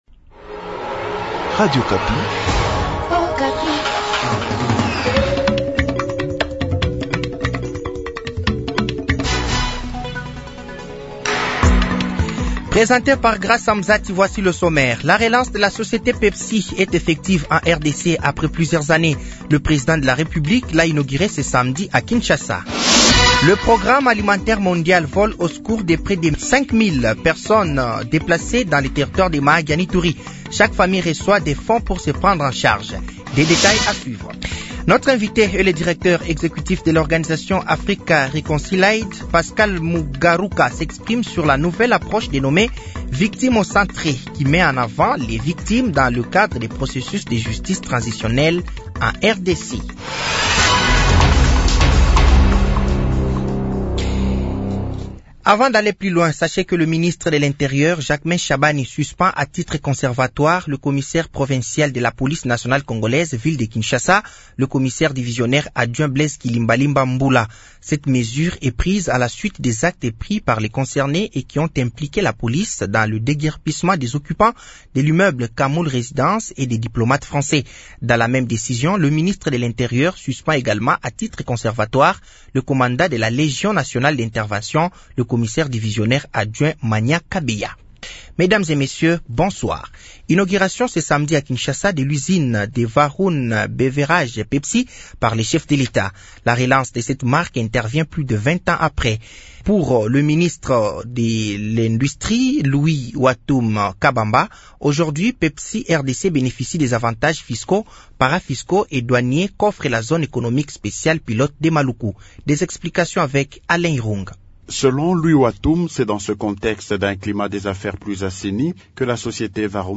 Journal français de 18h de ce samedi 31 août 2024